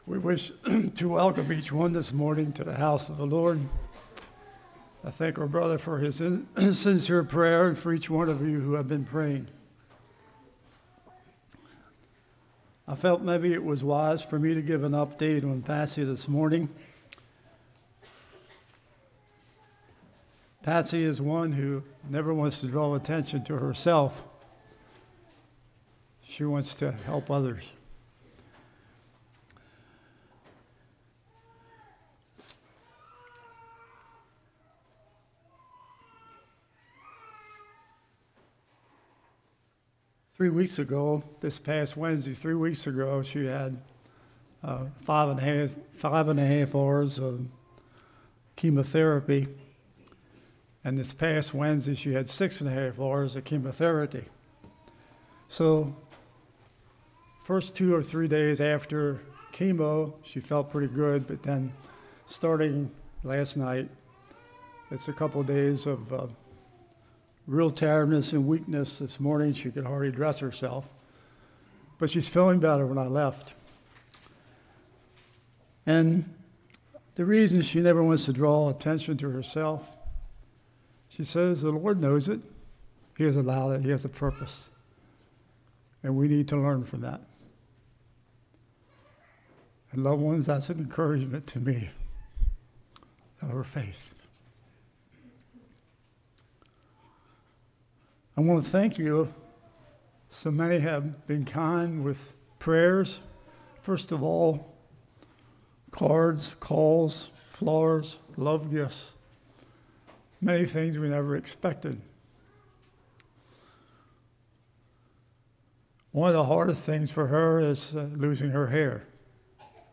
Passage: 1 Thessalonians 4:1-12 Service Type: Morning